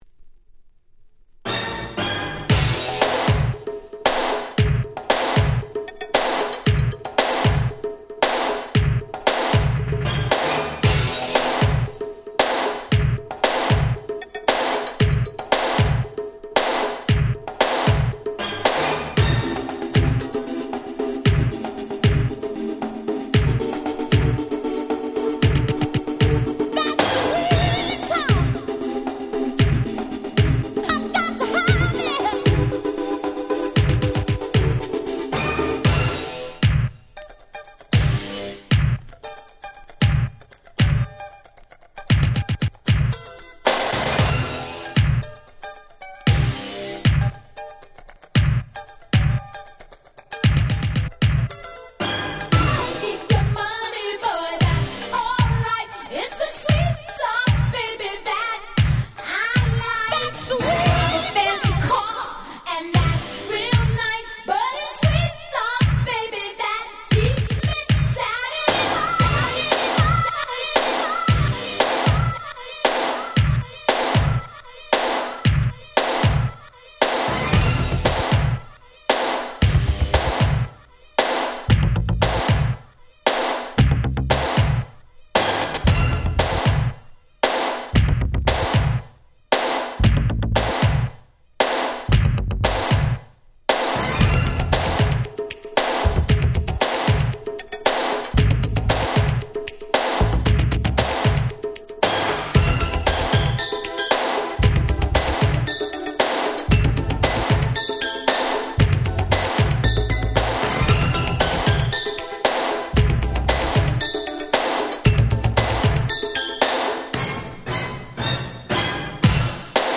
89' Smash Hit R&B / New Jack Swing !!
当時の空気感プンプンな「New Jack Swing前夜」的音使いな踊れる1曲！！
ニュージャックスウィング NJS ハネ系 ビルボードヒット 80's